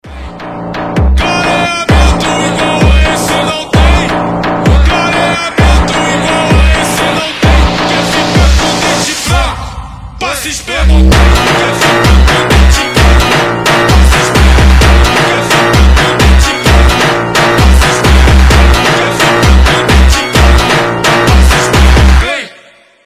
мощные
Мощный бразильский фонк